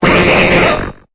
Cri de M. Mime dans Pokémon Épée et Bouclier.